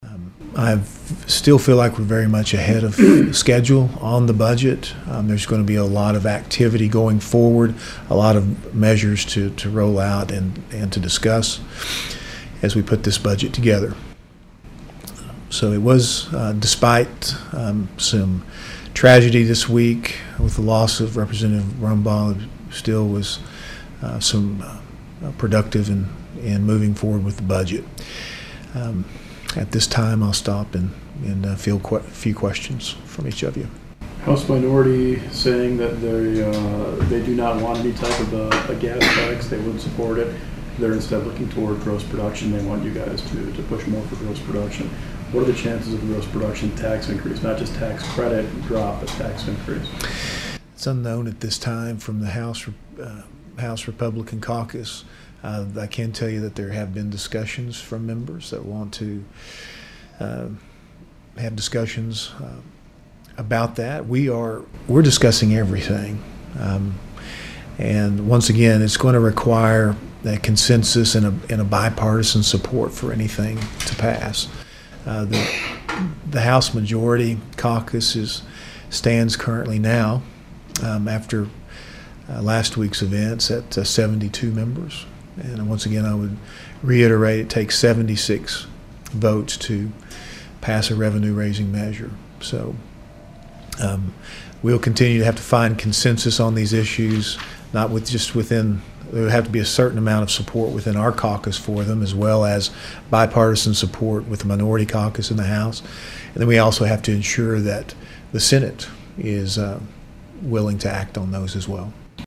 House Speaker Charles McCall told reporters Thursday he still believes the house is ahead of schedule, despite the recent death of Broken Arrow Rep. David Brumbaugh.